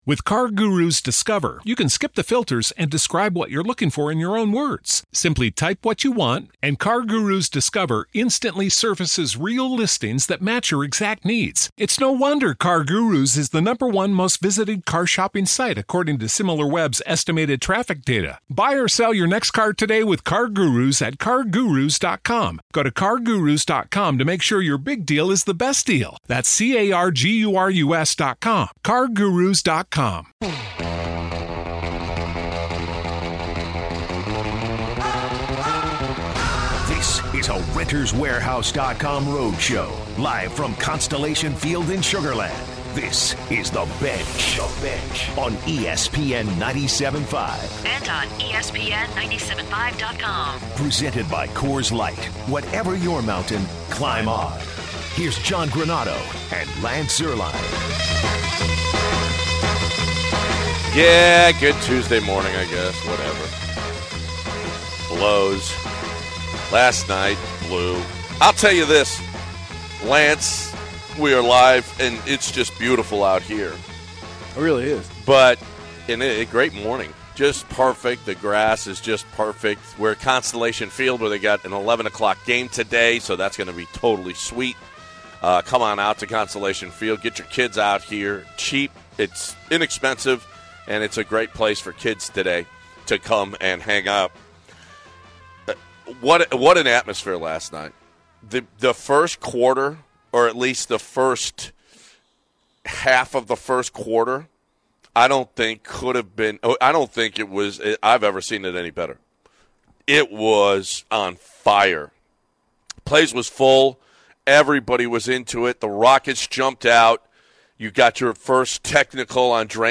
Live from Constellation Field